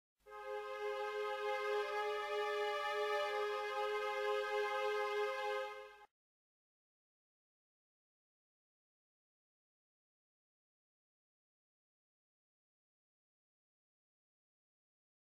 Eccovi alcune delle musiche che fanno parte del gioco.